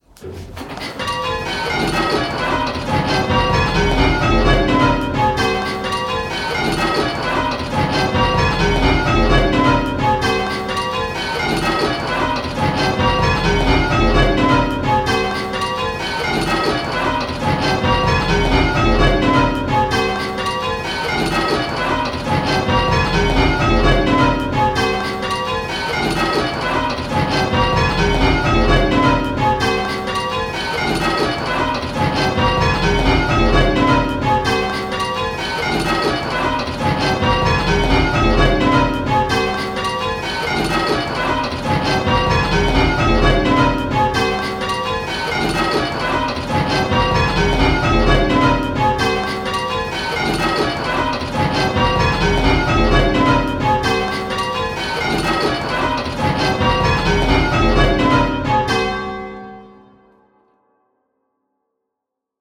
Listen to each clip and choose which bell is ringing early… the faults have been randomly placed, so there is no pattern and there might be consecutive clips that have the same fault!
Click the play button and listen to the rhythm of the 10 bells…. the rhythm will sound a bit lumpy!
Rounds-10-8E.m4a